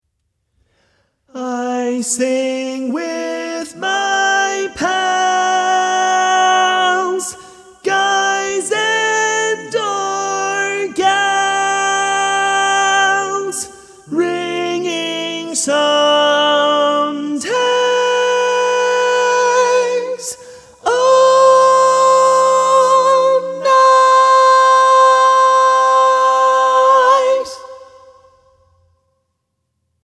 Key written in: C Major
Type: Barbershop